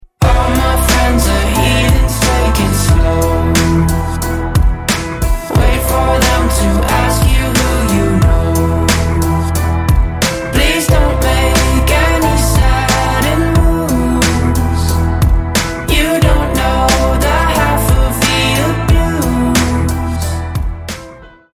• Качество: 128, Stereo
Rap-rock
alternative
Бодрый саундтрек